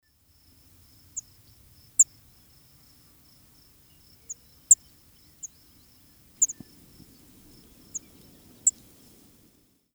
На этой странице вы найдете подборку звуков жаворонка – от звонких утренних трелей до нежных переливов.
Тревожные звуки жаворонка из Небраски